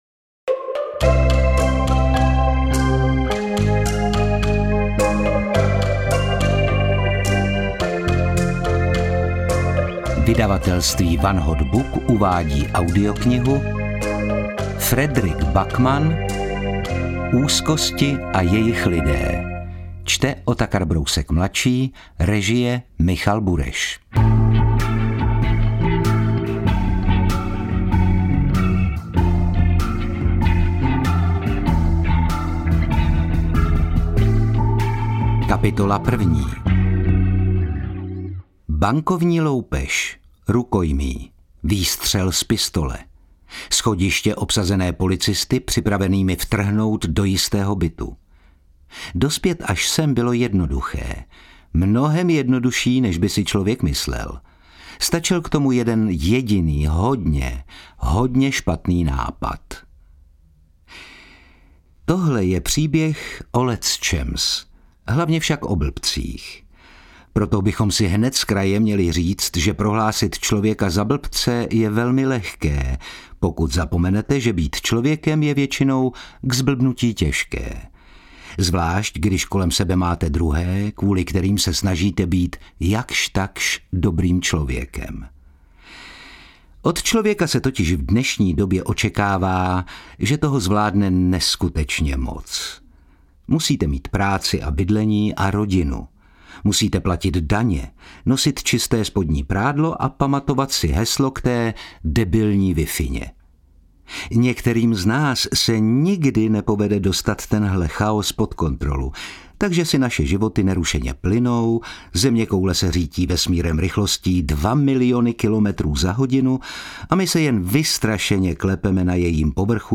Interpret:  Otakar Brousek ml.
V románové grotesce s lehkou detektivní zápletkou a silným důrazem na téma rodičovství stál interpret před úkolem poradit si s velkým množstvím různorodých a často hašteřivých postav.
AudioKniha ke stažení, 35 x mp3, délka 9 hod. 30 min., velikost 640,0 MB, česky